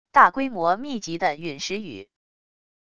大规模密集的陨石雨wav音频